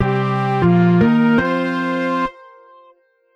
Sound Effects
Breaking News